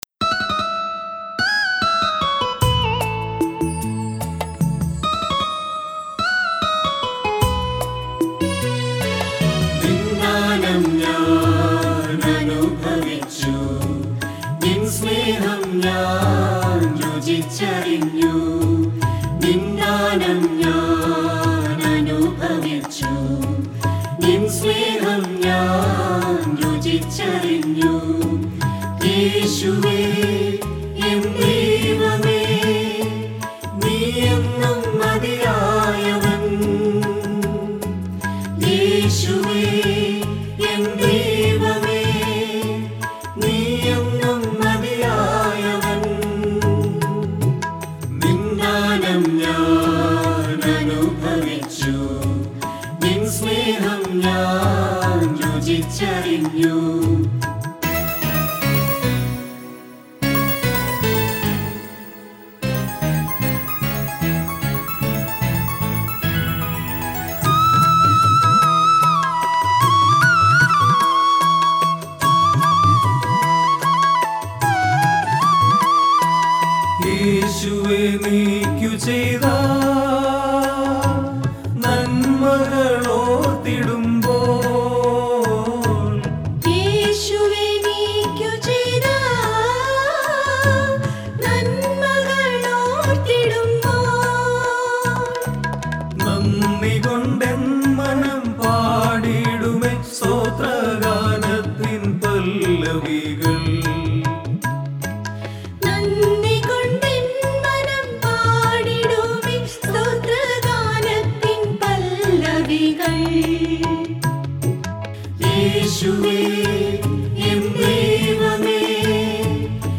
Devotional Songs Jan 2021 Track 4